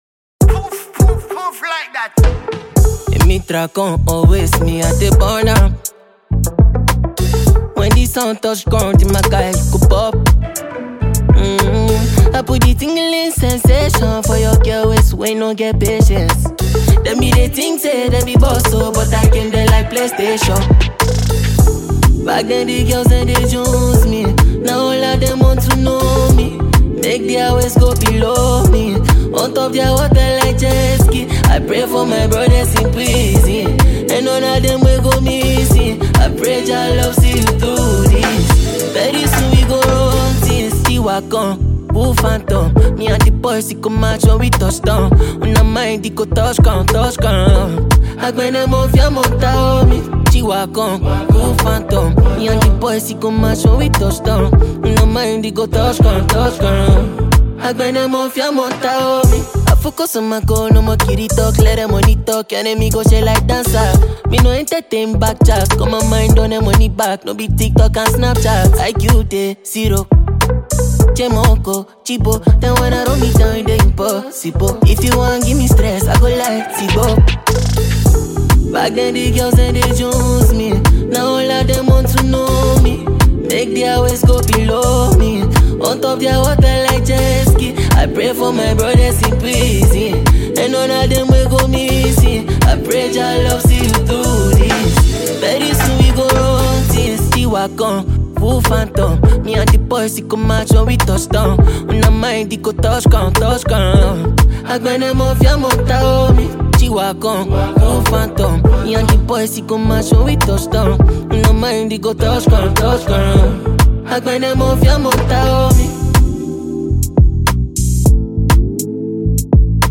Ghanaian afrobeat songwriter and musician